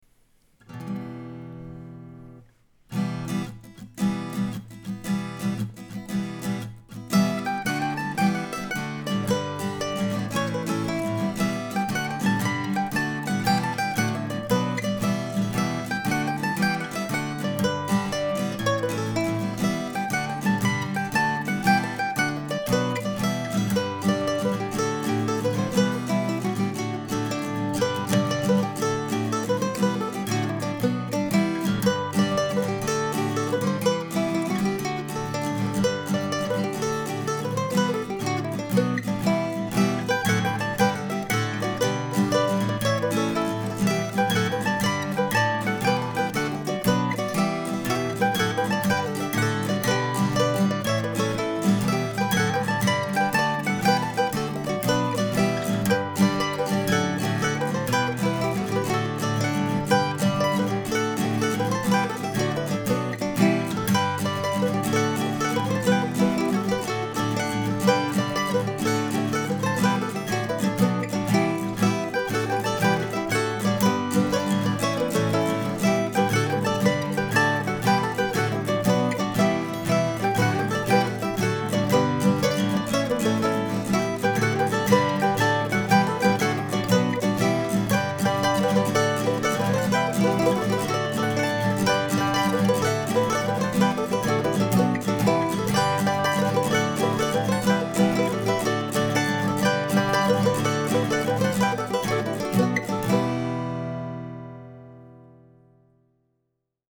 The recording is a little wobbly but still fits within my flexible demo quality standard.
A careful listen will reveal that the tune is played 3 times. The first time a short guitar intro is followed by the melody on mandolin. The second time through a harmony mandolin part is added, more or less as written in the pdf above. The third time through I doubled the melody an octave down (with a couple of changes) during the A sections but I added a third harmony part in the B section.